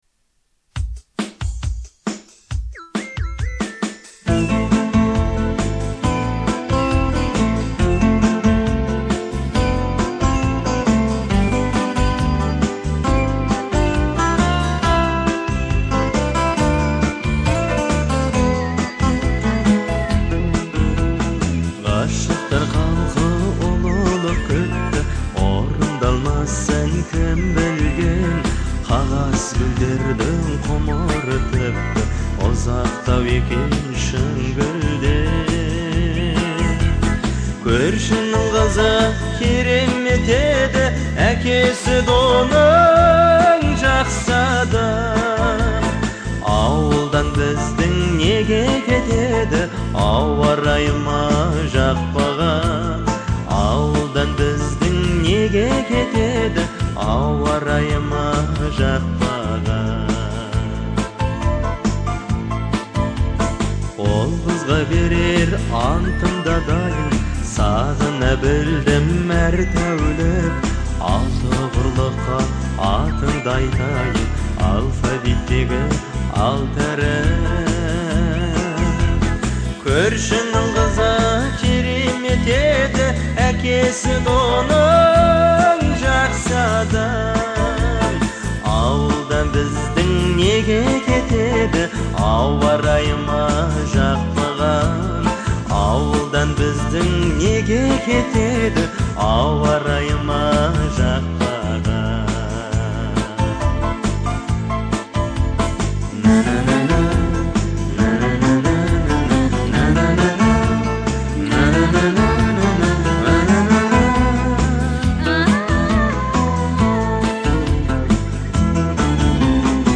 это популярная казахская песня в жанре поп
Песня выделяется яркой мелодией и запоминающимся хором